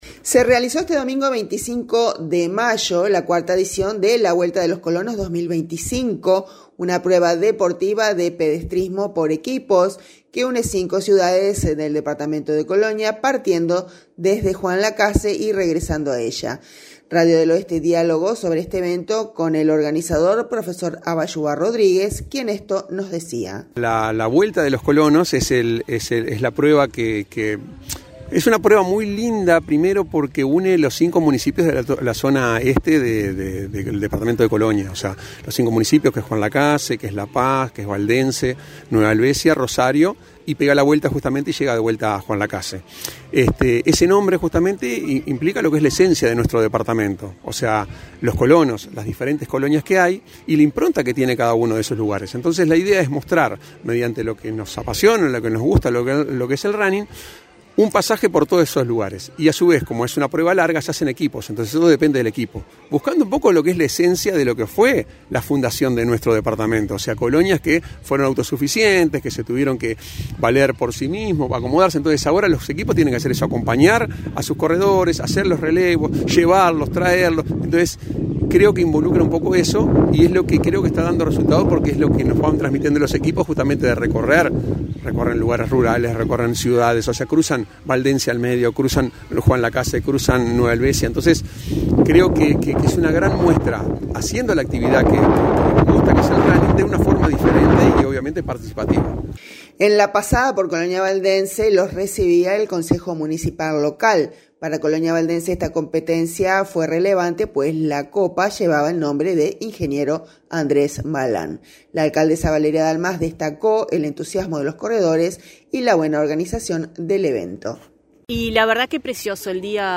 La alcaldesa Valeria Dalmás destacó el entusiasmo de los corredores y la buena organización del evento.